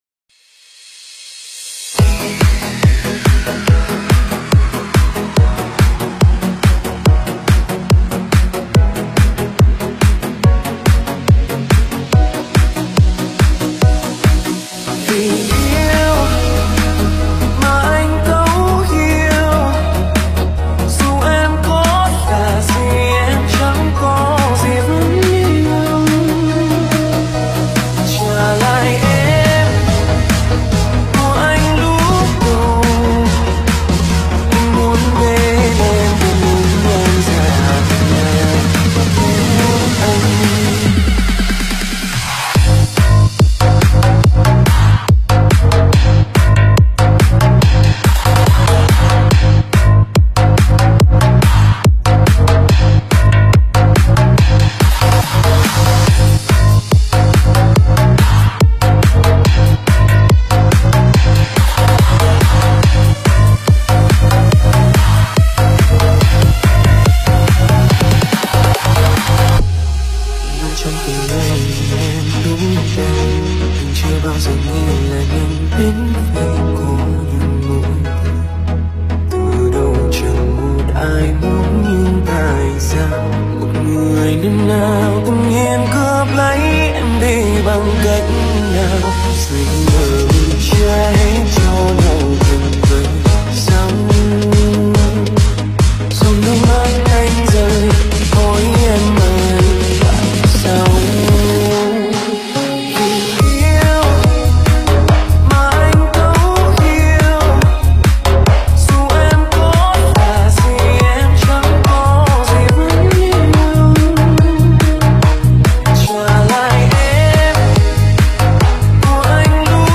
Nonstop Việt Mix